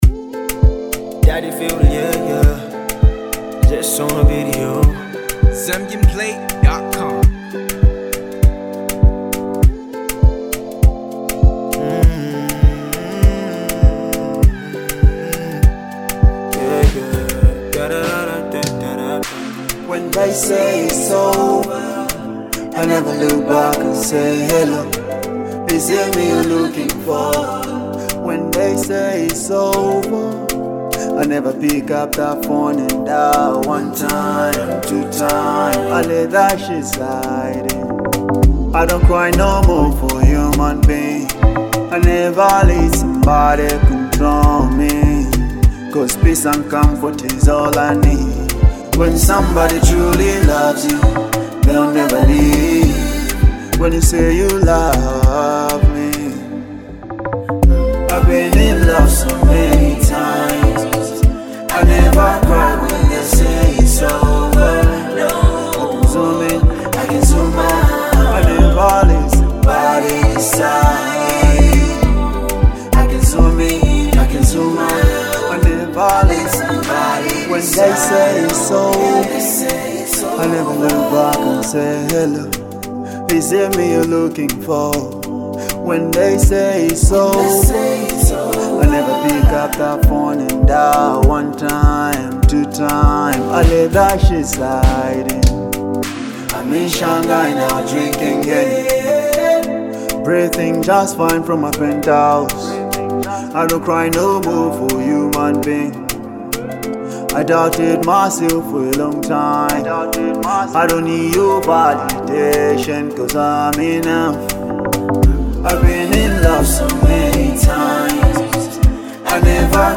With simple lyrics, a catchy vibe, and real-life energy